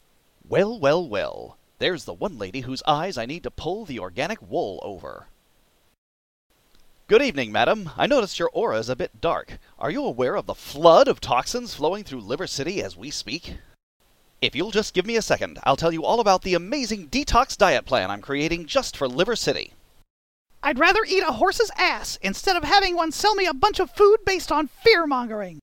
intro dialog snippet I recorded for the video.
Chemistry_Lesson_intro_dialog.mp3